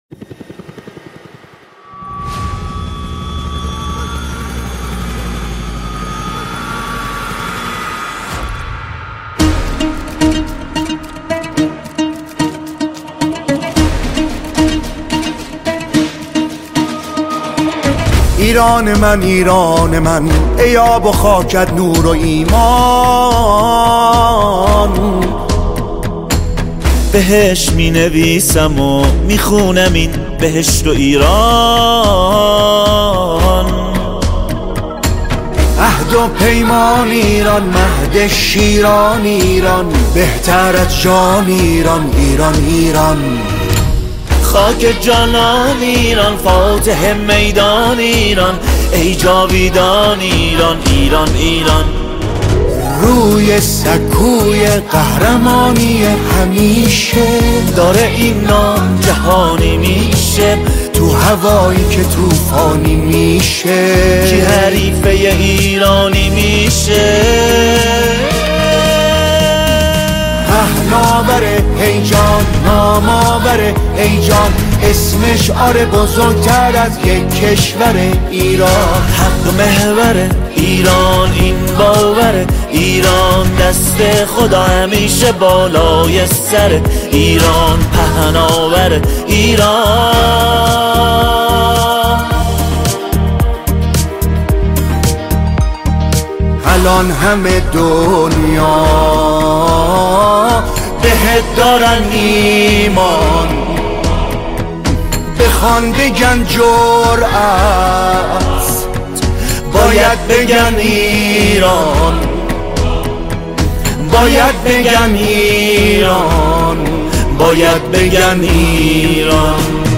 نماهنگ زیبای حماسی